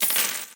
Coins_Hidden.ogg